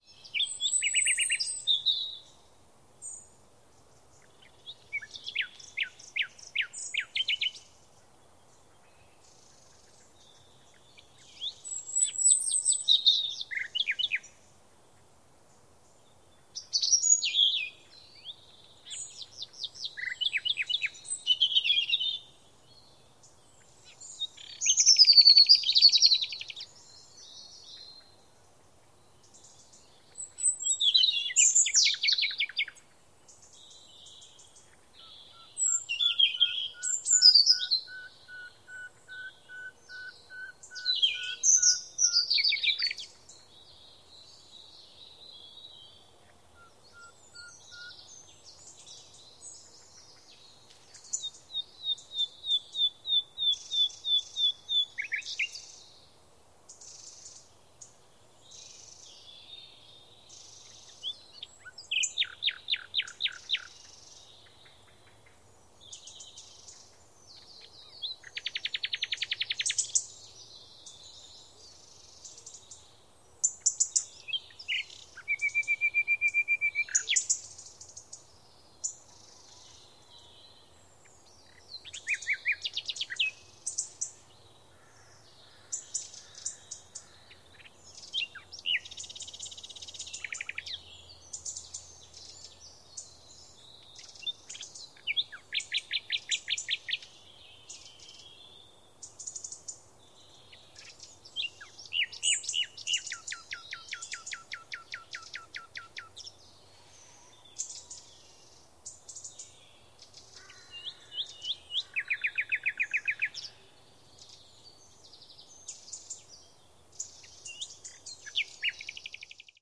Звуки пения птиц
Атмосферный звук с пением птиц в весеннем лесу или на даче